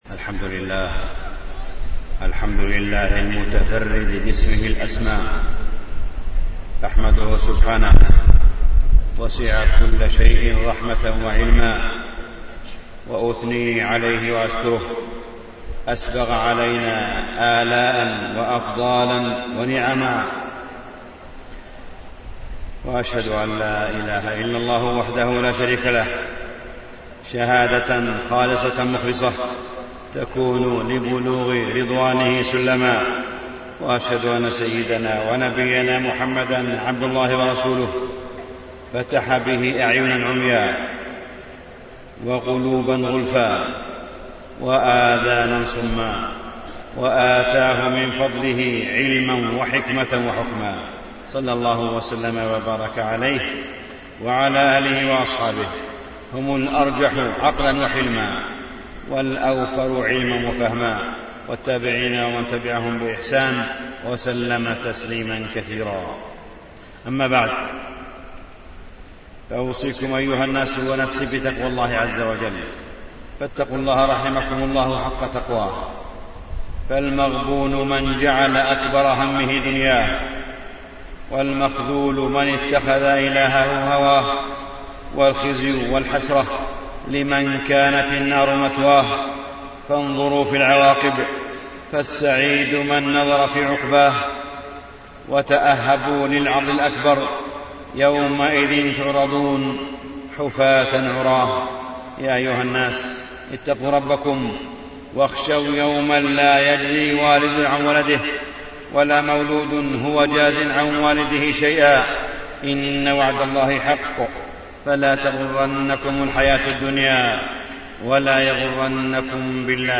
تاريخ النشر ٣ ذو الحجة ١٤٣٣ هـ المكان: المسجد الحرام الشيخ: معالي الشيخ أ.د. صالح بن عبدالله بن حميد معالي الشيخ أ.د. صالح بن عبدالله بن حميد وقفة وتأملات في نصرة المصطفى